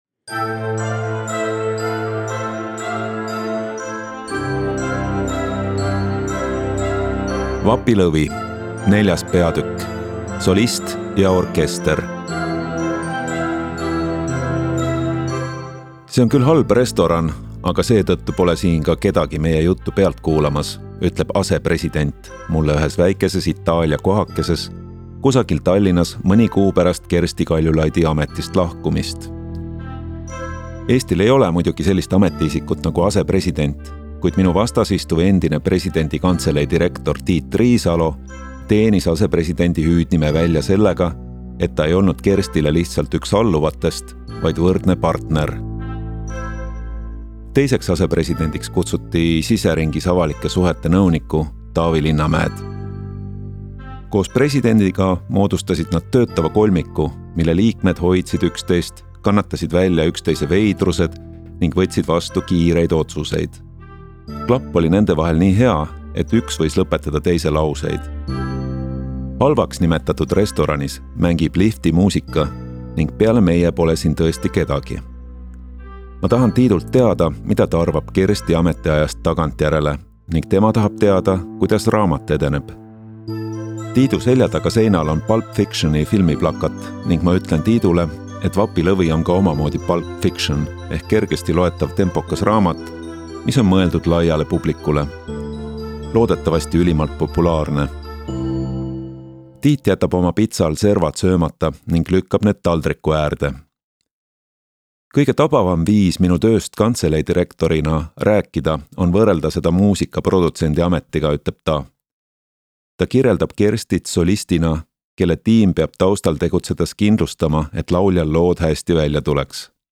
Osta kõik peatükid audioraamat e-raamat 11,99 € Telli raamat audioraamat e-raamat paberraamat Järgmine lugu 5. peatükk.